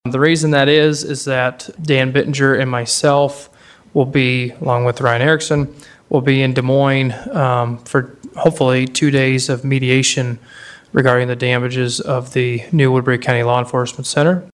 SUPERVISOR MARK NELSON SAYS IT’S BECAUSE SEVERAL COUNTY OFFICIALS WILL BE IN DES MOINES FOR A SPECIAL MEETING: